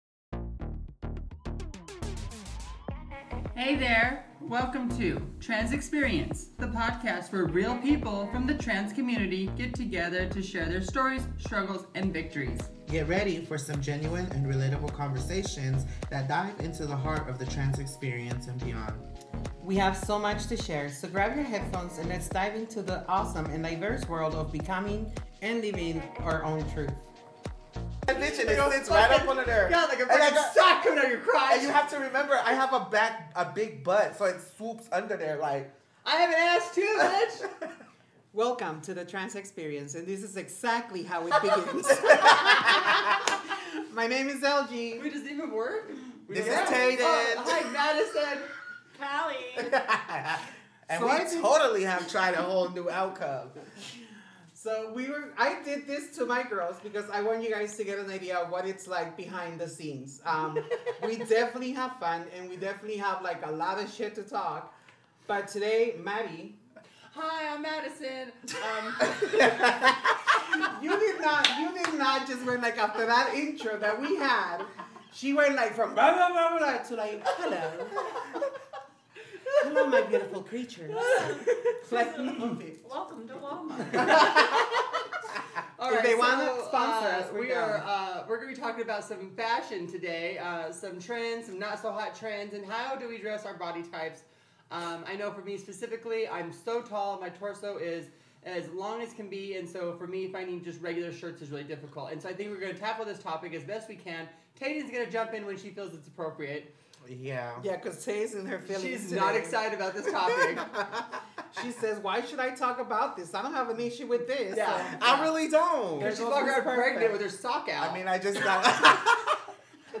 In a lively, uncut episode, the girls discussed how they built their closets to reflect their individual styles. Discover how they transformed their wardrobe into a true representation of themselves.